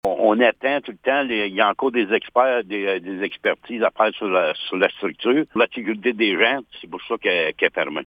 Près de quatre mois se sont écoulés et la tour est toujours fermée à l’heure actuelle. Laurent Fortin, maire de la Municipalité de Blue Sea, réitère les faits :